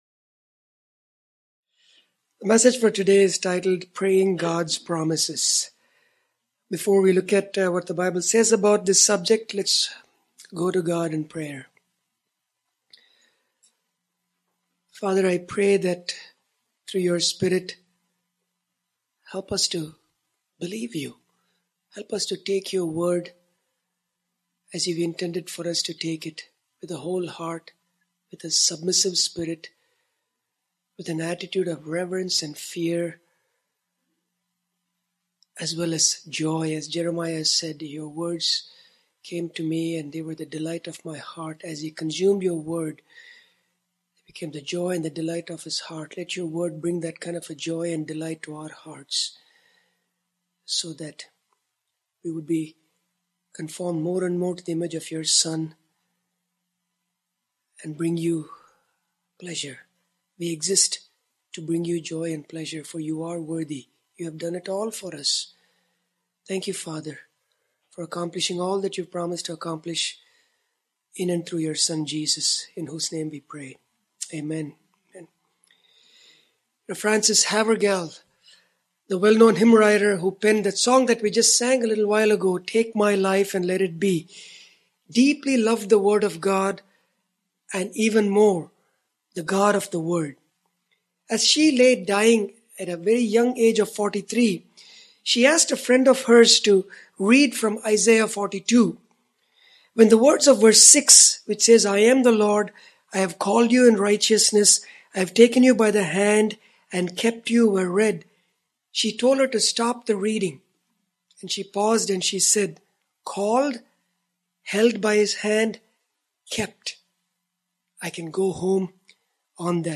When we meditate on God’s promises — and pray them back to him — they give strength, clarity, and hope in the darkest times. This sermon explores 10 specific promises that can guide us in times of need.